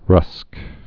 (rŭsk)